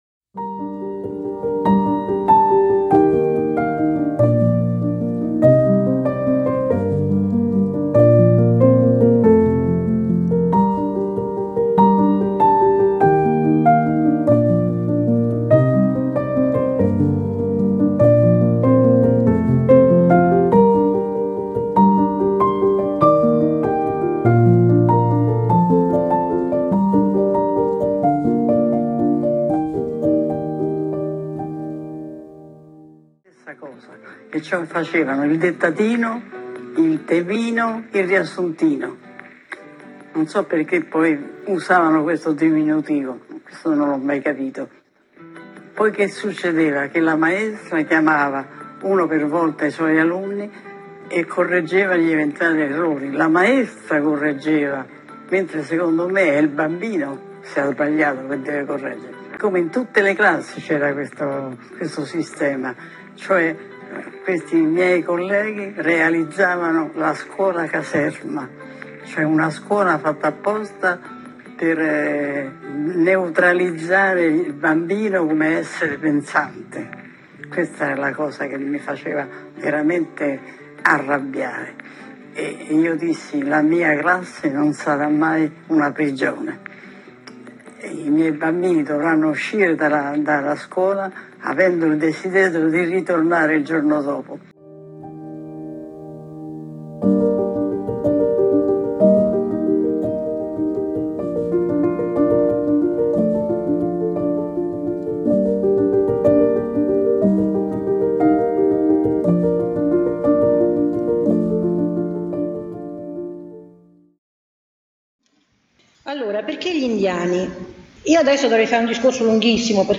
Un audio dalla plenaria Scarica Dopo i cantieri un documento di sintesi Le statistiche: slide con i numeri di Cantieri 2022